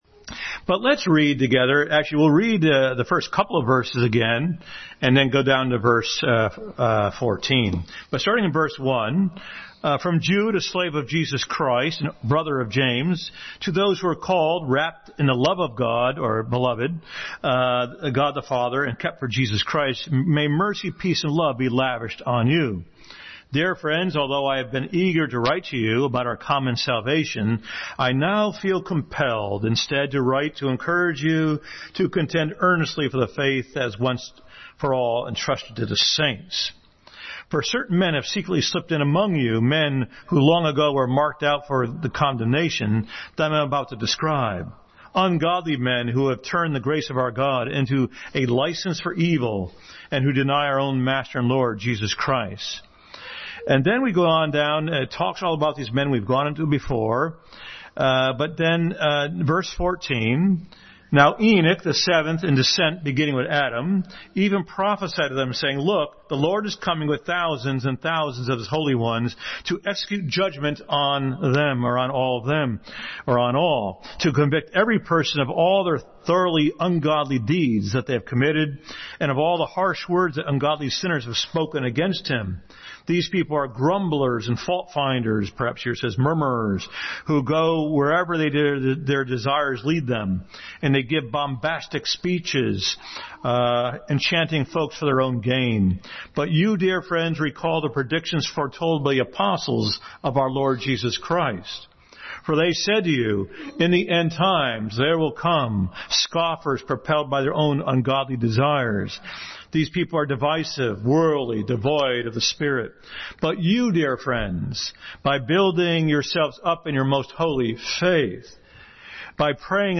Jude 14-25 Passage: Jude 14-25, 1-3, 2 Thessalonians 3-7, Acts 20:17-32, 24:1, 2 Peter 2:1-3, 3:3-7 Service Type: Family Bible Hour